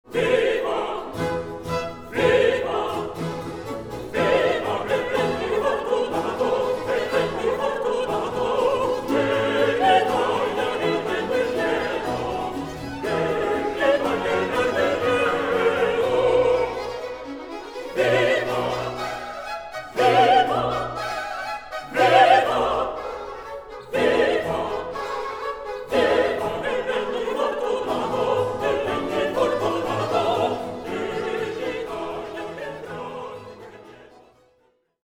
Opera in three acts, HWV 26
soprano
tenor